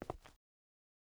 Concrete Walk - 0006 - Audio - Stone 06.ogg